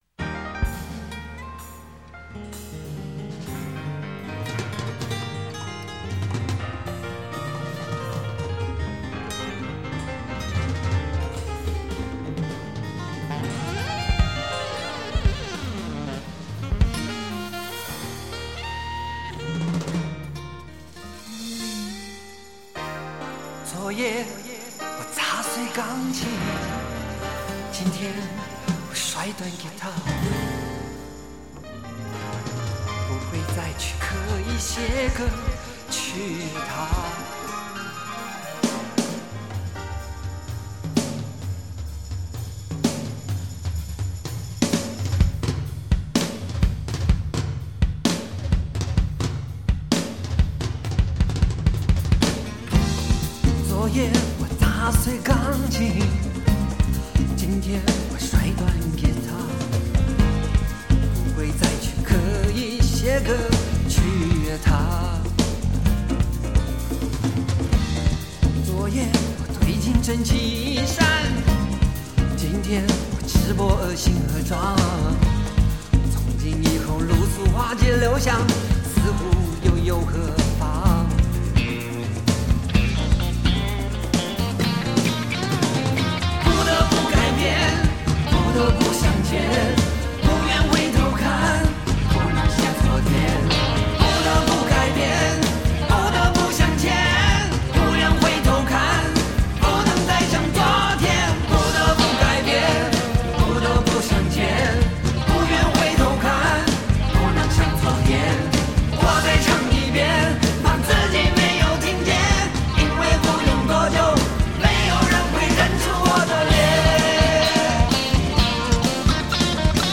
远赴英国伦敦录音，十首歌曲精彩绝伦，曲风兼融古典深情、激越狂放、浪漫隽永。